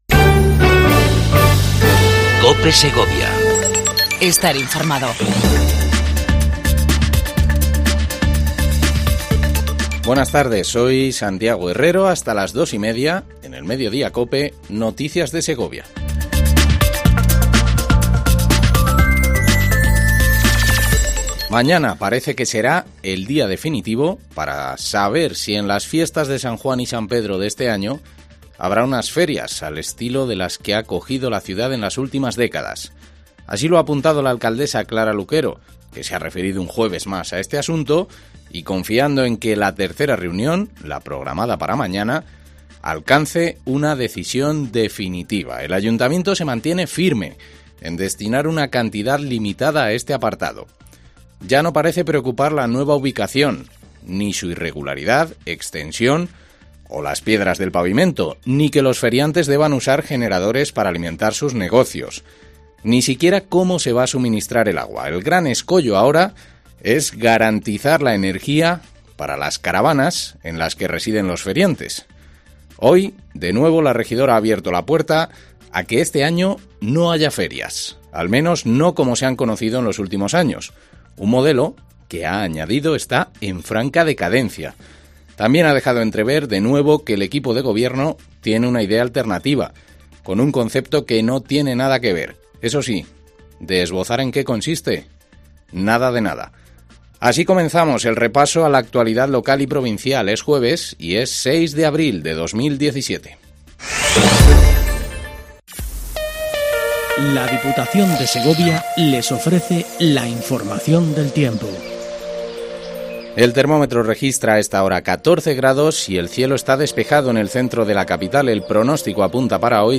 INFORMATIVO MEDIODIA COPE EN SEGOVIA 06 04 17